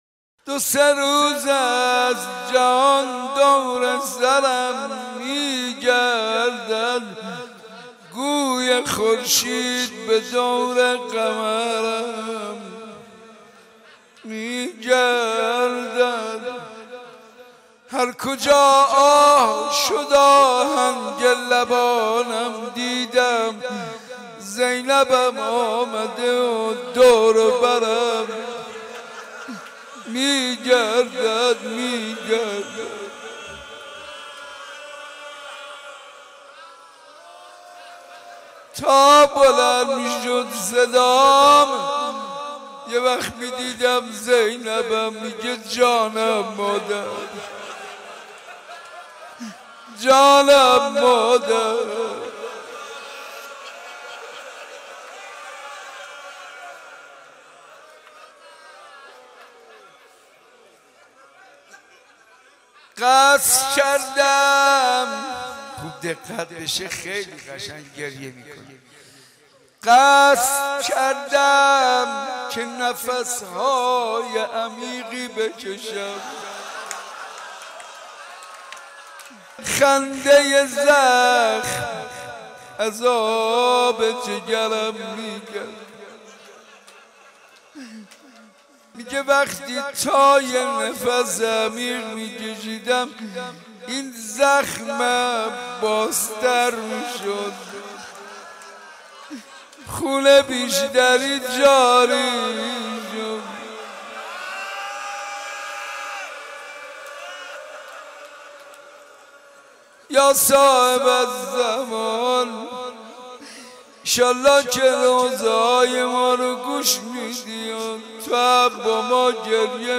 مراسم زیارت عاشورا روز سوم فاطمیه اول حسینیه صنف لباس فروشان